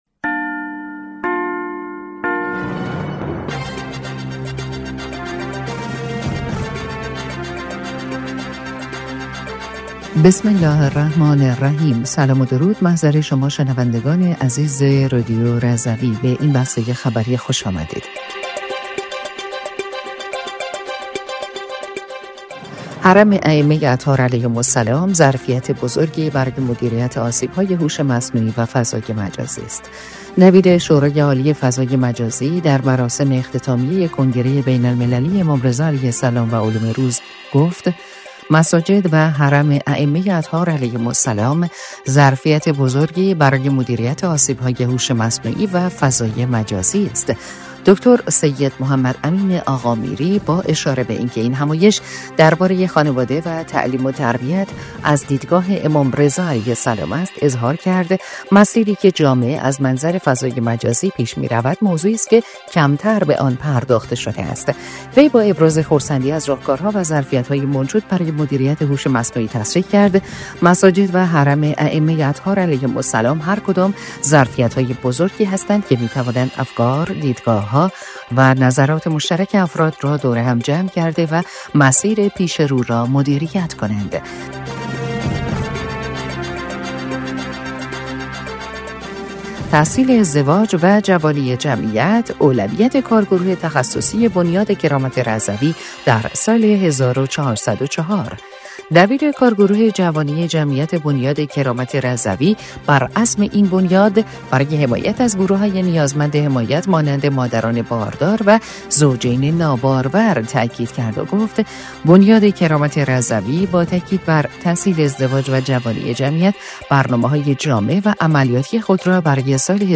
بسته خبری یکشنبه ۱۱ خرداد ۱۴۰۴ رادیو رضوی/